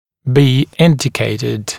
[biː ‘ɪndɪkeɪtɪd][би: ‘индикейтид]быть показанным, предписанным